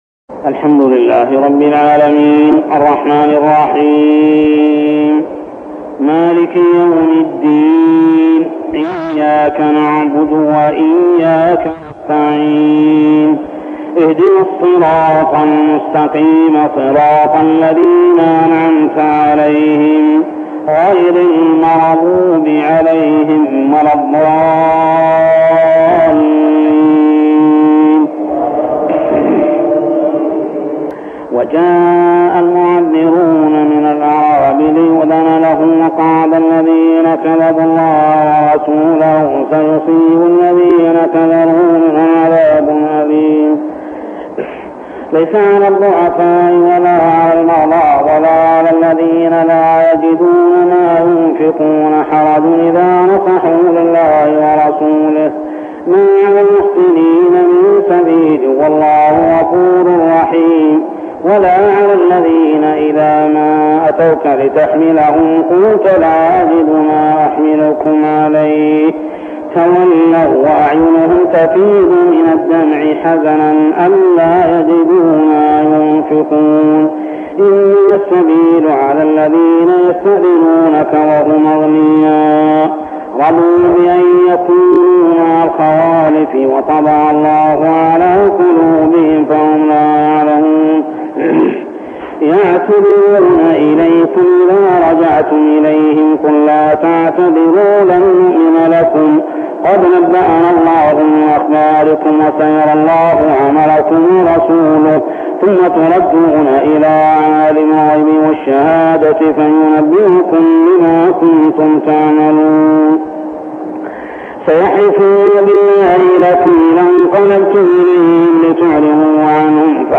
صلاة التراويح عام 1403هـ سورتي التوبة 90-129 و يونس 1-25 | Tarawih prayer Surah At-Tawbah and Yunus > تراويح الحرم المكي عام 1403 🕋 > التراويح - تلاوات الحرمين